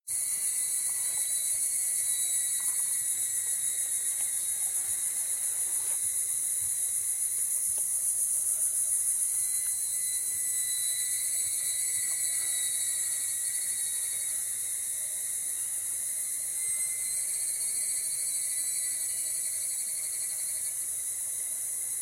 Les ruines mayas de Tikal au milieu de la jungle valent aussi pour leur ambiance sonore.
cigarra.mp3